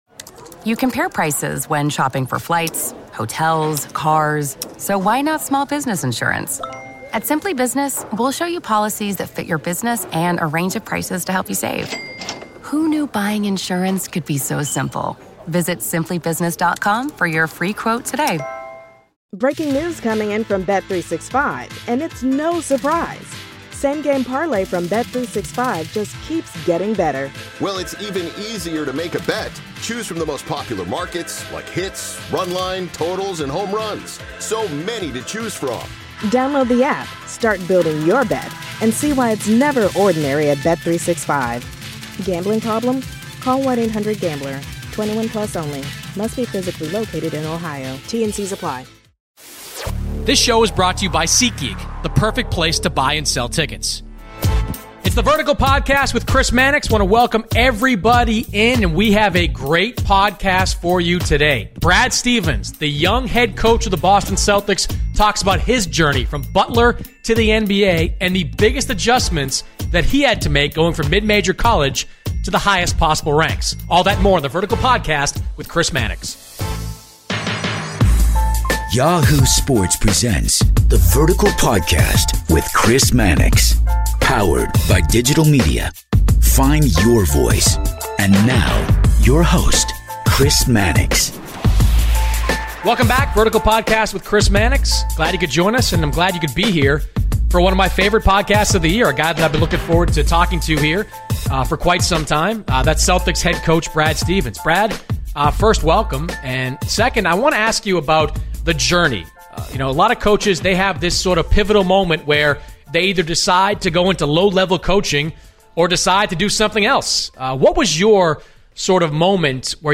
Boston Celtics Head Coach Brad Stevens The Crossover NBA Show SI NBA Basketball, Sports 4.6 • 641 Ratings 🗓 1 March 2016 ⏱ 39 minutes 🔗 Recording | iTunes | RSS 🧾 Download transcript Summary On this week's episode of The Vertical Podcast with Chris Mannix, Boston Celtics head coach Brad Stevens takes a seat with Chris to discuss his mercurial rise through the basketball ranks.